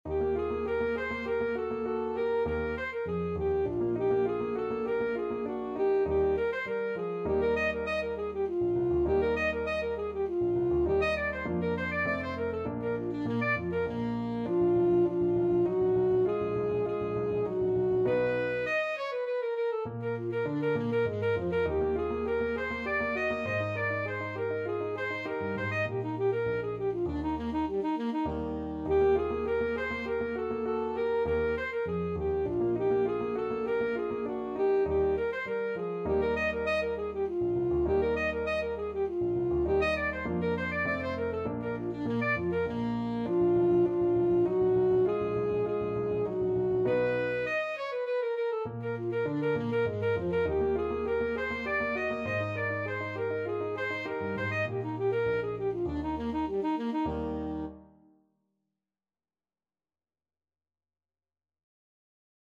Alto Saxophone
Eb major (Sounding Pitch) C major (Alto Saxophone in Eb) (View more Eb major Music for Saxophone )
3/4 (View more 3/4 Music)
Classical (View more Classical Saxophone Music)
paganini_minuetto_ASAX.mp3